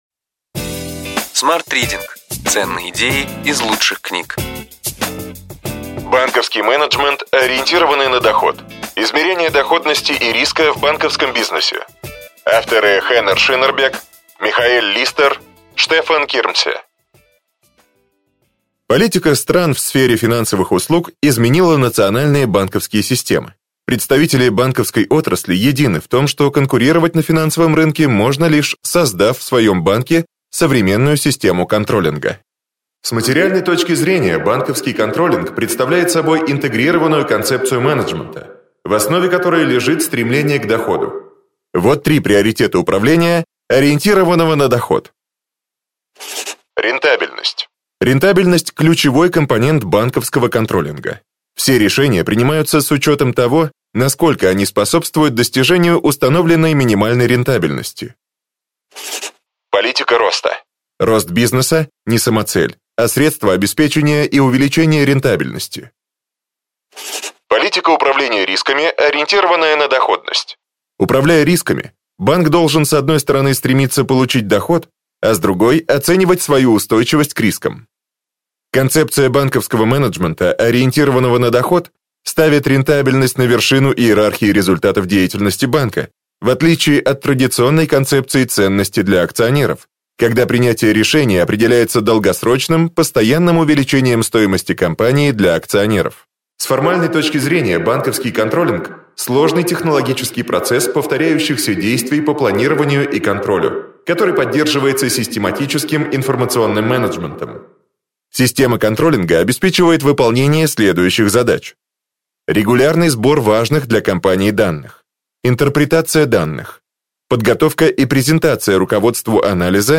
Аудиокнига Ключевые идеи книги: Банковский менеджмент, ориентированный на доход.